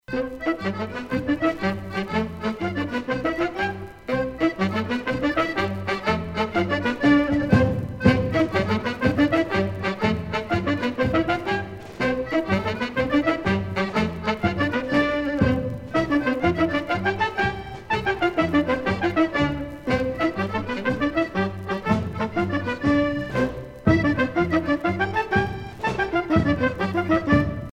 danse : matelote ;
Pièce musicale éditée